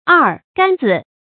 二杆子 èr gān zǐ
二杆子发音
成语注音ㄦˋ ㄍㄢ ㄗㄧ